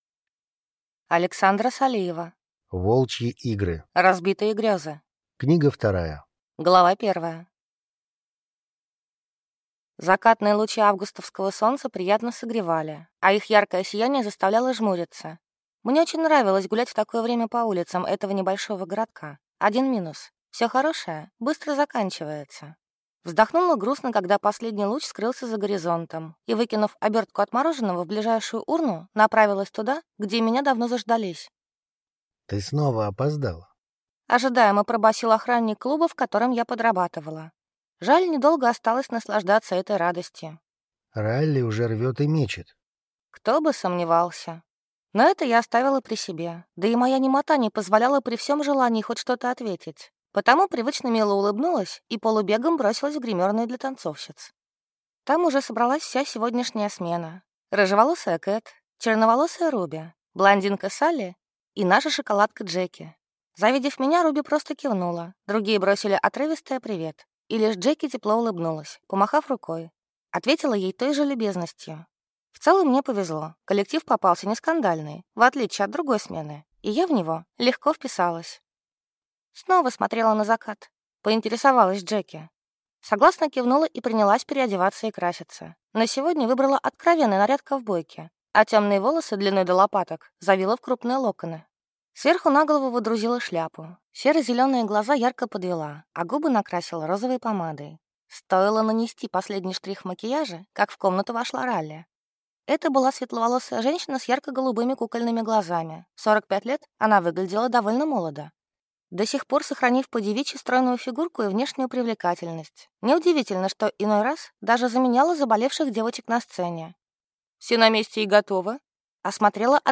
Аудиокнига Желанная для серого волка | Библиотека аудиокниг